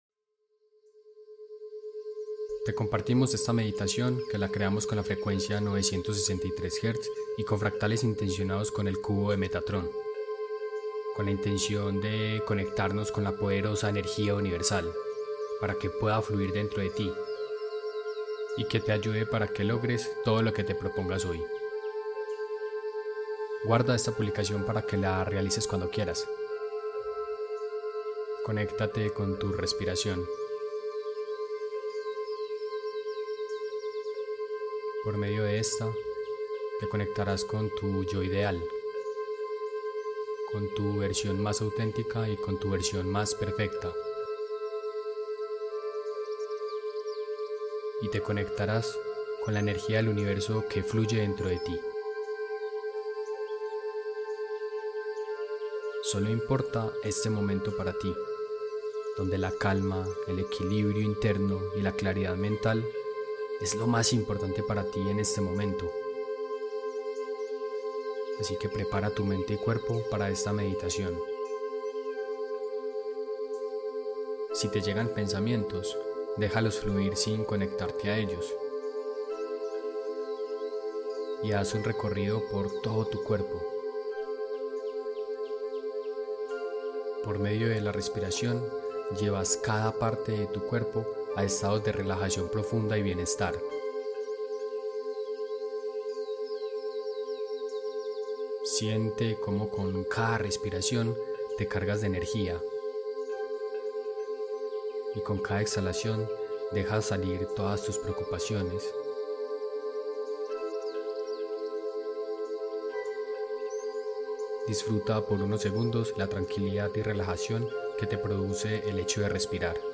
Meditación con frecuencia 963 Hz sound effects free download